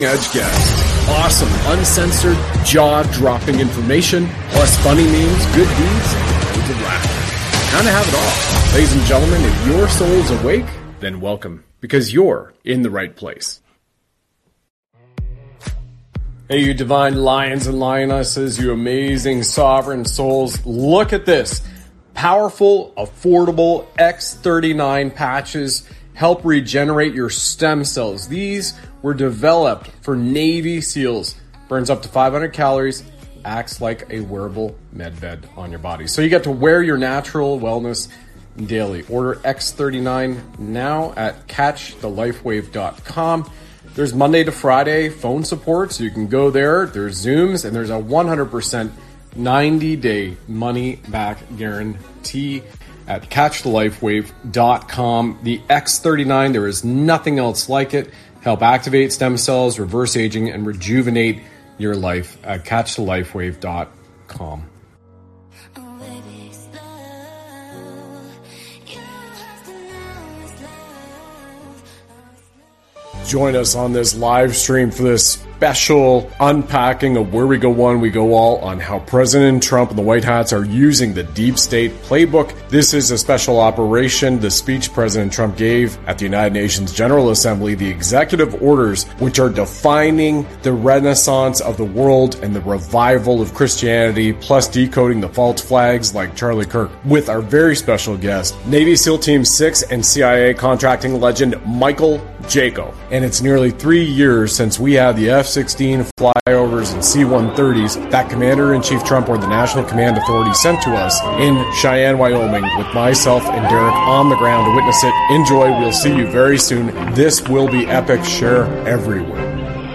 live stream show